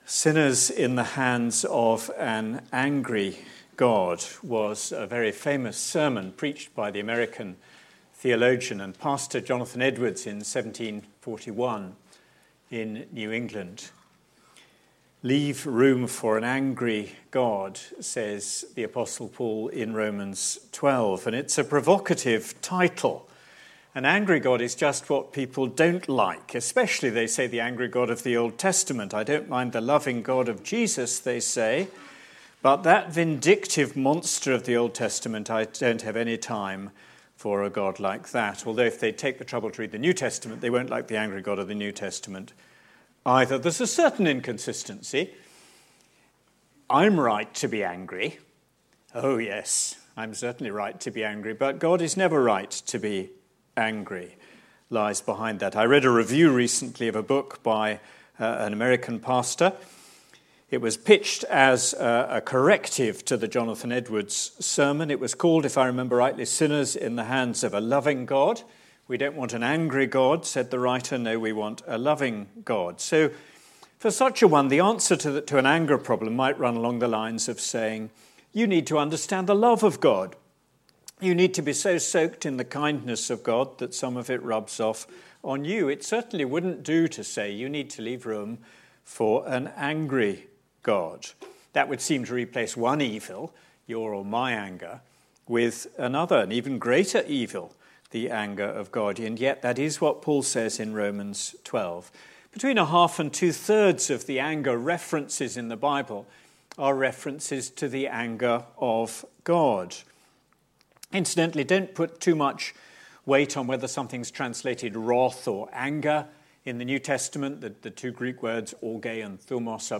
Anger: being cross, being ChristlikeResidential Conference, February 2018